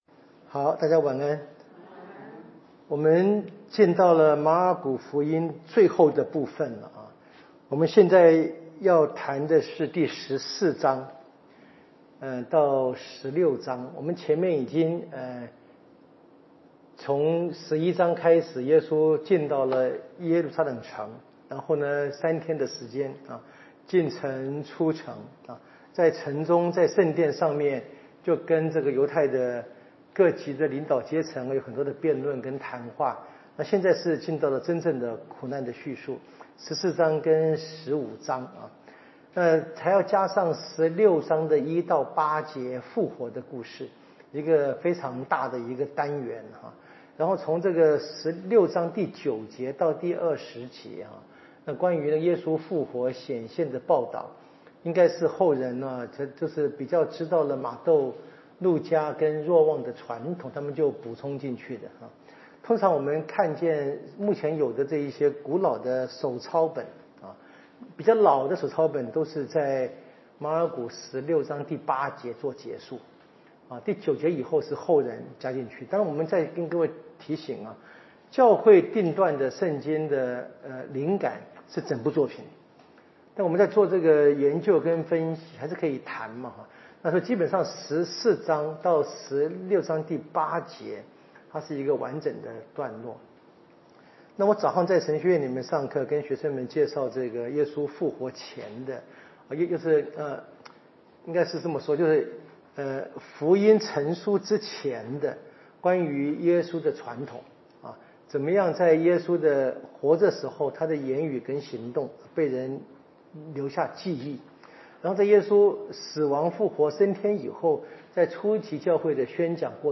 【圣经讲座】《马尔谷福音》